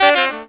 HARM RIFF 12.wav